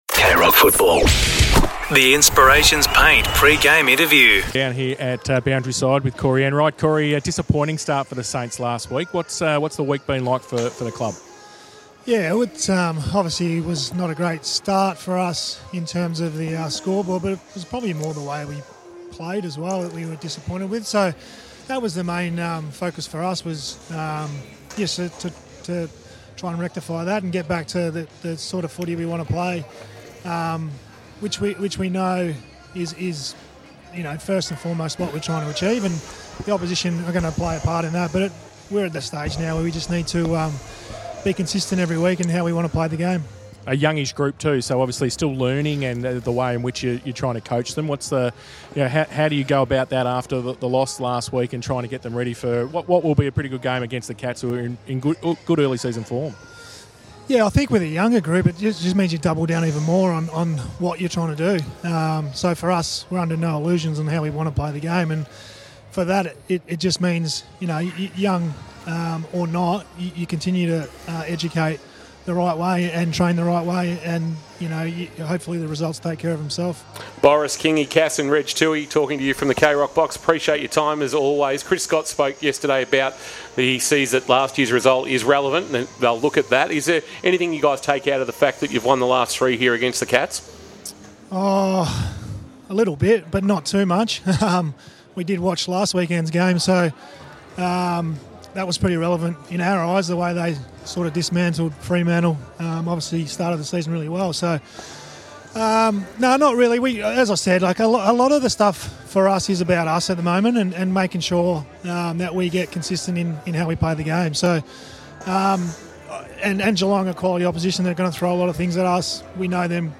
2025 - AFL Round 2 - St Kilda vs. Geelong: Pre-match interview - Corey Enright (St Kilda assistant coach)